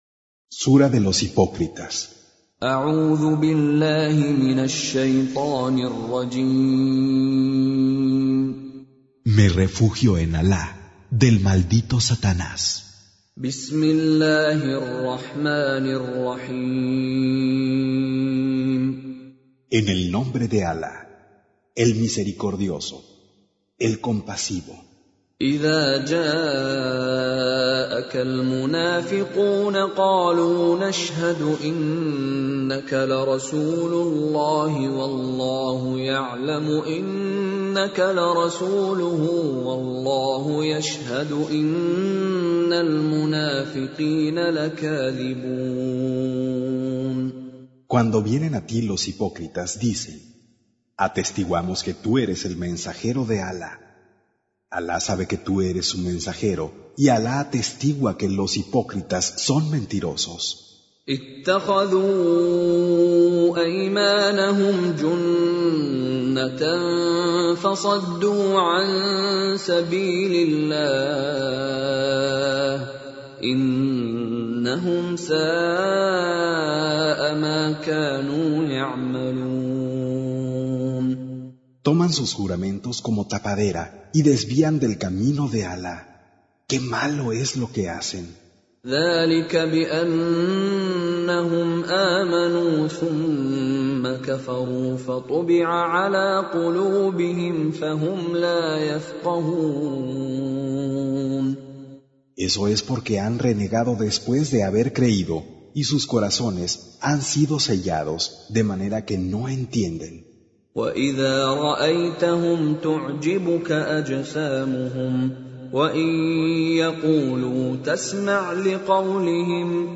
Surah Sequence تتابع السورة Download Surah حمّل السورة Reciting Mutarjamah Translation Audio for 63.